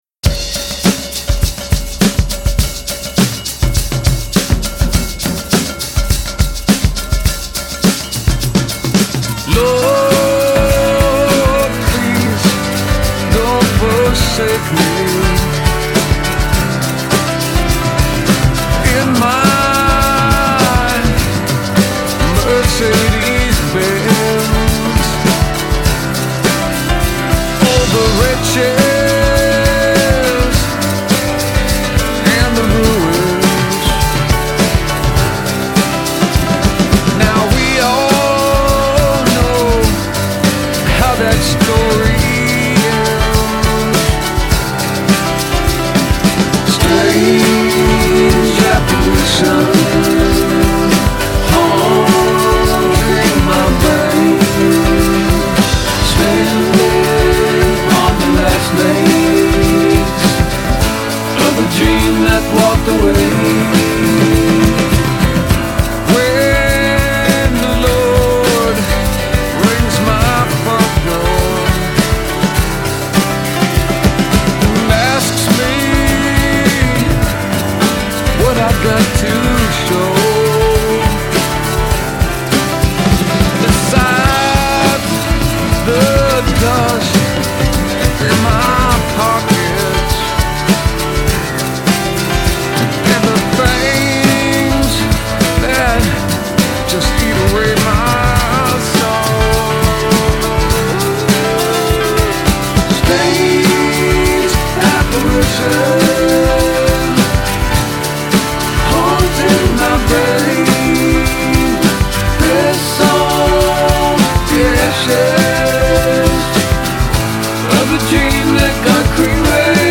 It’s mostly built around tight funk and hip-hop tracks